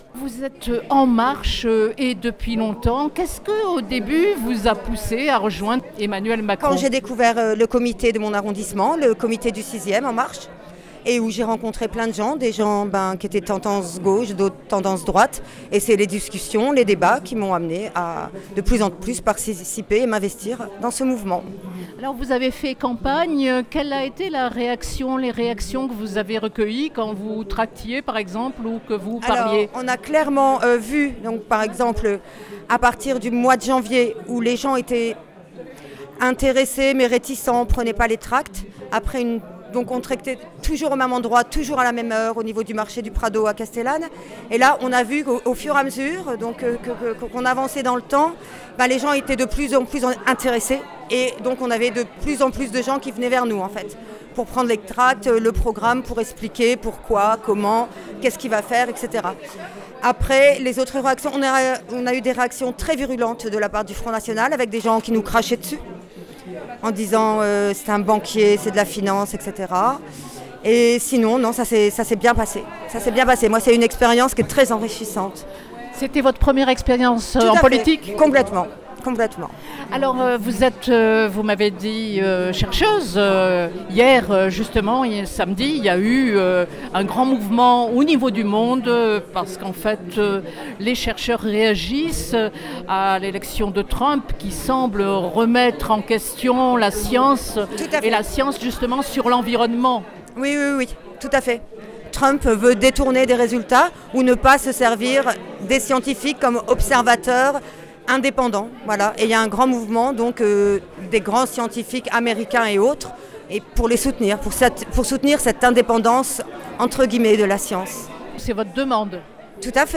Marseille – Au QG d’En Marche la victoire est savourée: réactions